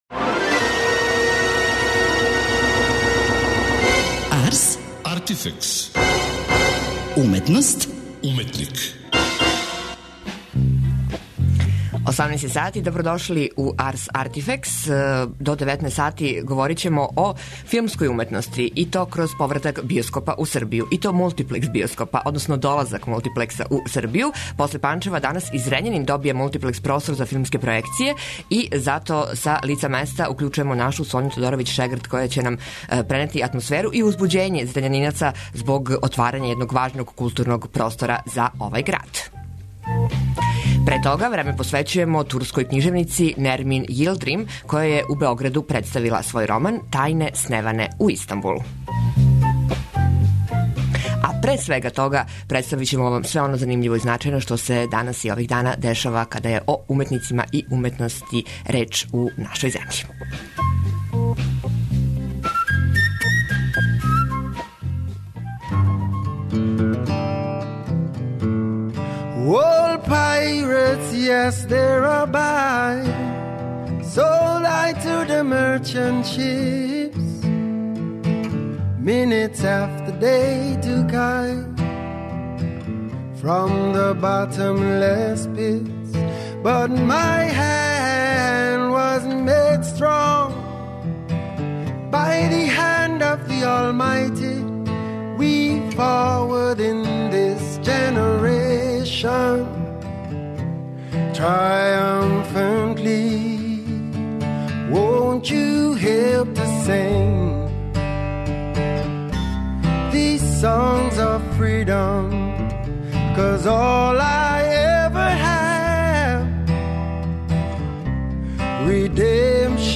После Панчева, данас и Зрењанин добија мултиплекс простор за филмске пројекције, па у емисији планирамо директно укључење у свечано отварање овог важног културног простора за Зрењанинце.